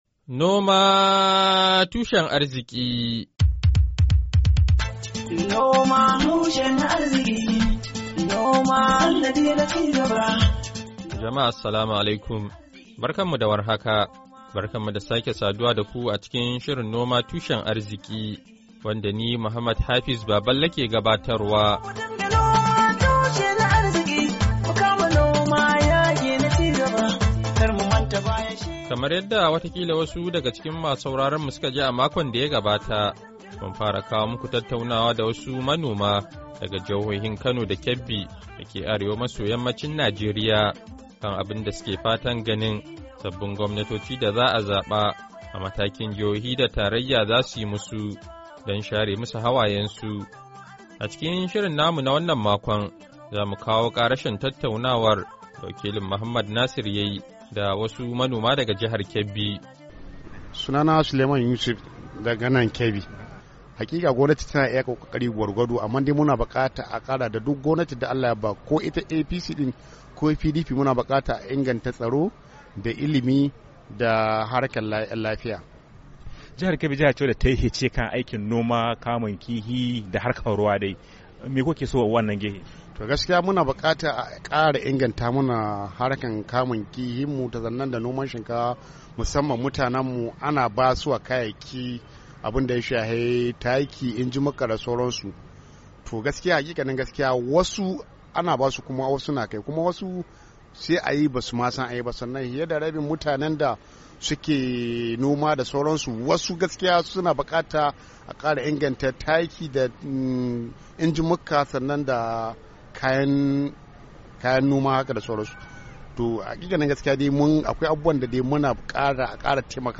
Shirin na wannan makon, ya tattauna da wasu manoma daga jihar Kebbi da ke arewa maso yammacin Najeriya, kan irin matsalolin da ke damunsu da kuma abubuwan da suke fatan sabuwar gwamnati da za'a zaba ta yi mu su.